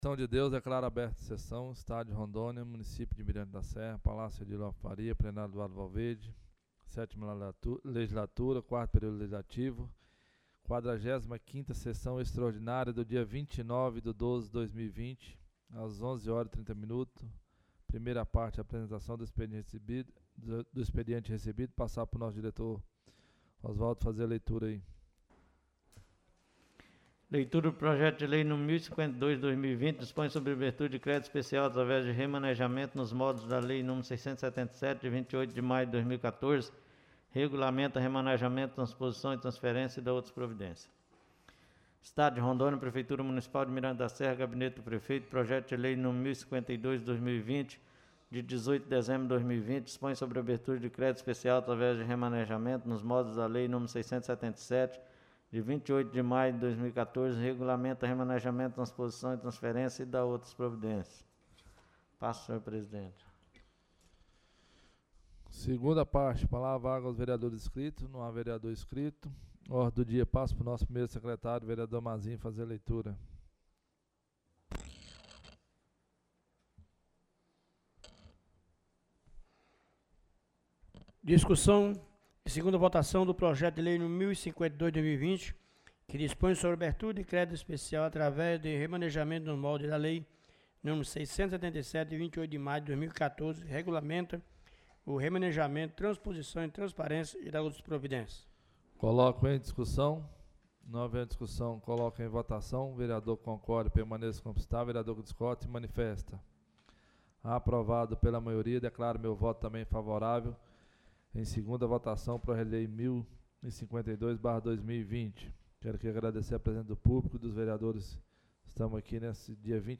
45ª Sessão Extraordinária da 27ª Sessão Legislativa da 7ª Legislatura